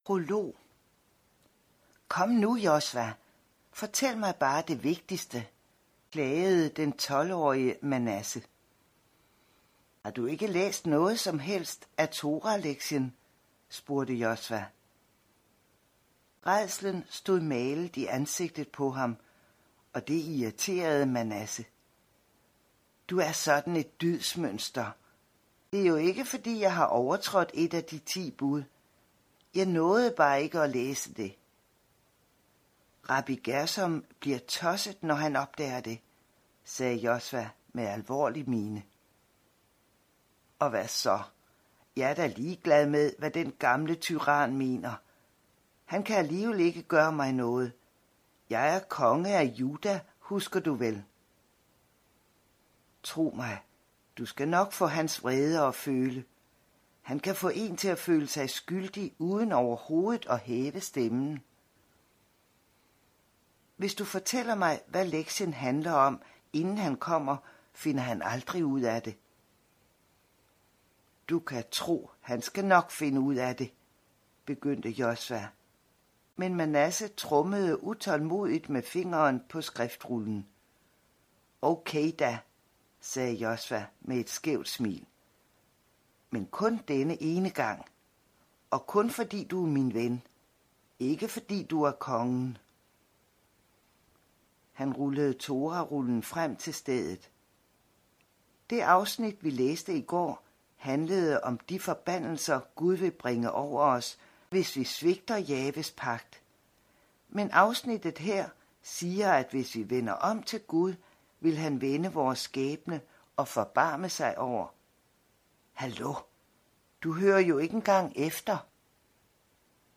Hør et uddrag af Fædrenes tro Fædrenes tro Konge Krøniken IV Format MP3 Forfatter Lynn Austin Lydbog E-bog 99,95 kr.